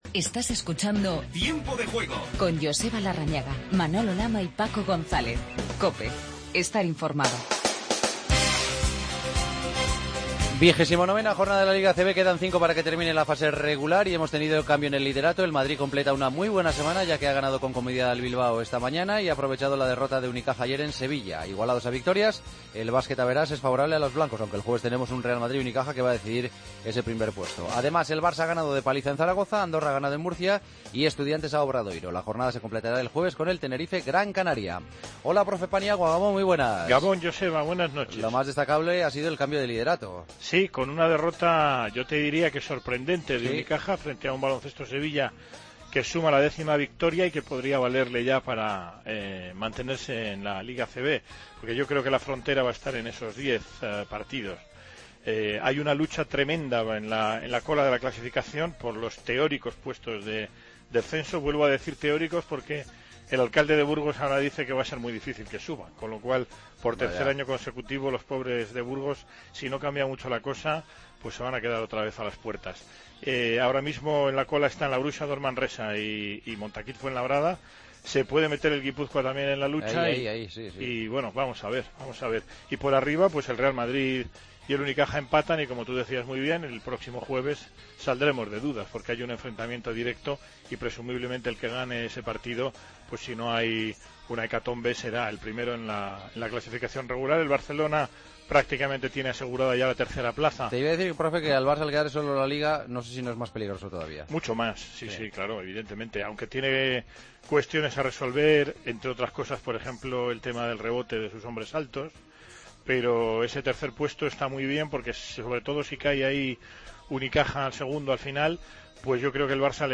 Noticias de baloncesto